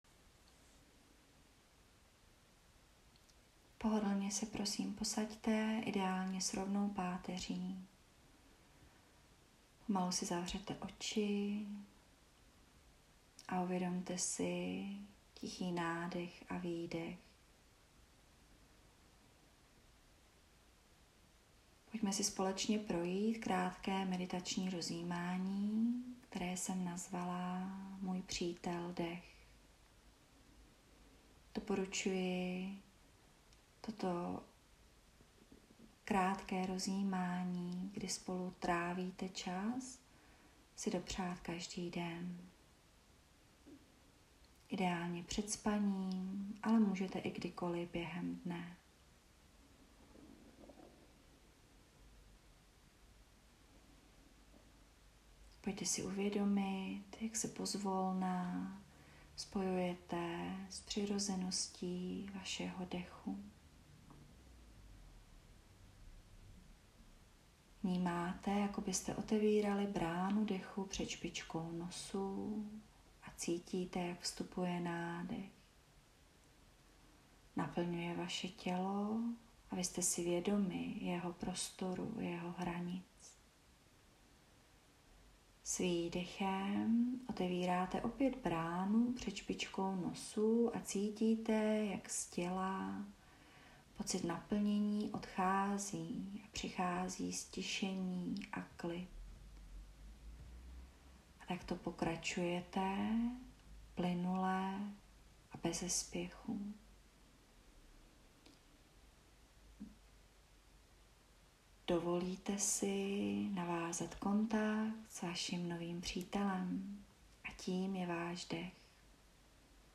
Meditace-Přítel-Dech.mp3